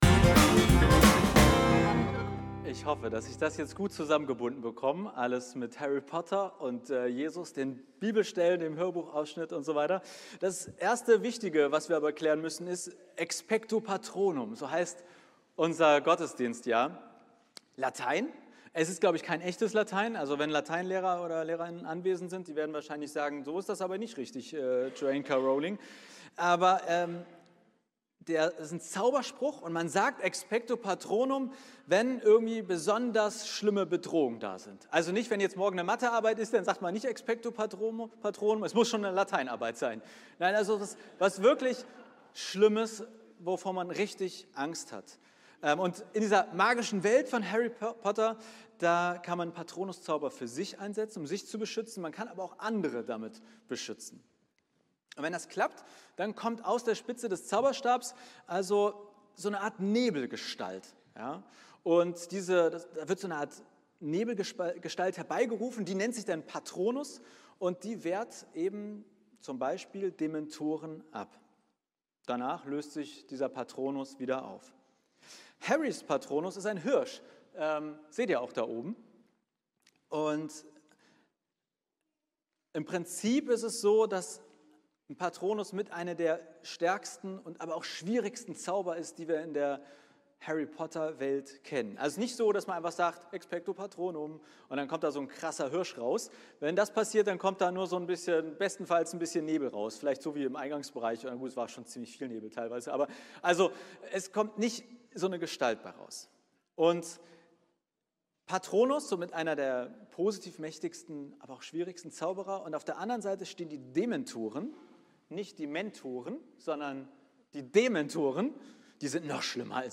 Was haben Gebete und der Zauberspruch „Expecto Patronum“ gemeinsam? Was sind dementoriale Kräfte und wieso würde Jesus heute vermutlich sagen: „Ich bin dein Patronus“? Meine Predigt aus unserem Harry-Potter-Gottesdienst in der Winterausgabe.